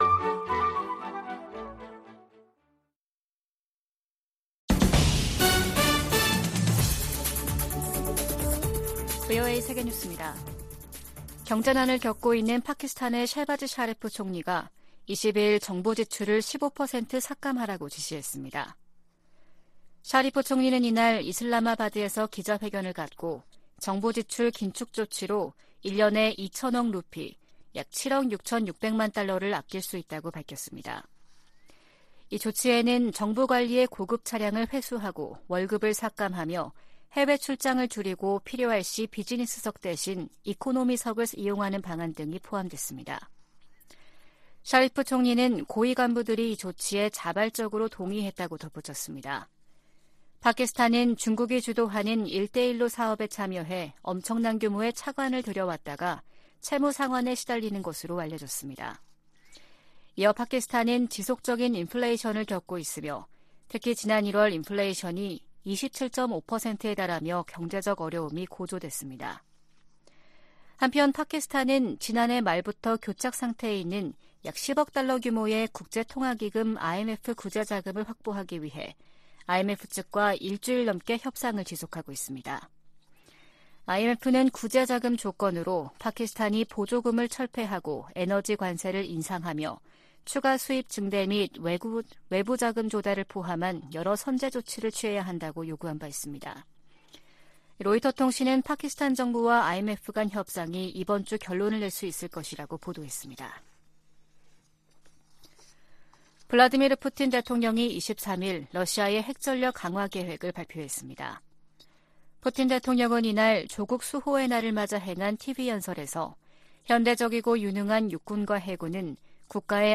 VOA 한국어 아침 뉴스 프로그램 '워싱턴 뉴스 광장' 2023년 2월 24일 방송입니다. 미 국무부가 북한의 최근 ICBM 발사를 거듭 규탄한 가운데 국방부는 북한의 계속되는 탄도미사일 시험 발사가 역내 불안정을 야기한다고 비판했습니다. 북한이 최근 발사한 방사포 사정거리 안에 있는 군산 주한미군 공군기지가 당장 응전할 준비가 돼 있다고 밝혔습니다. 캐나다 정부가 북한의 미사일 발사를 ‘공격행위’로 규정하고, 역내 안정 노력을 기울이겠다고 밝혔습니다.